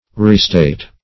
Restate \Re*state"\ (r?*st?t"), v. t.